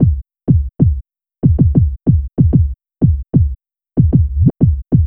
Track 13 - Kick Beat 01.wav